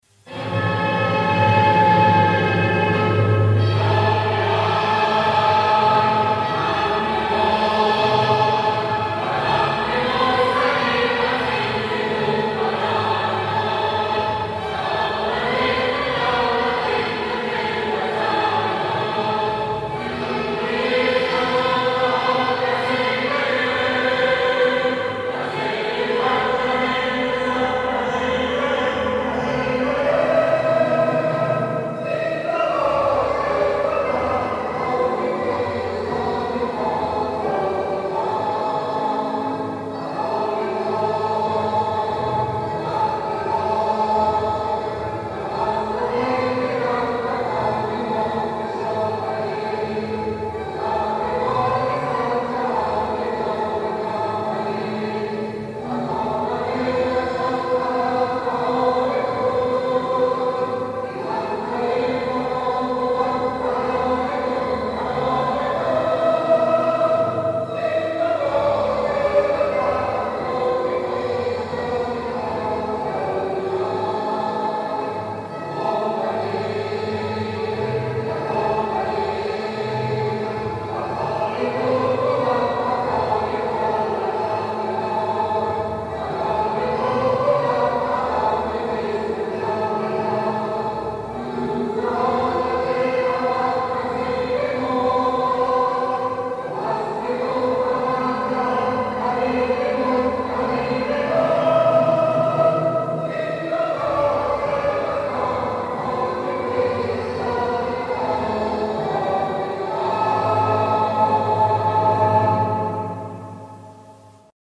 Tajikistan_anthem.mp3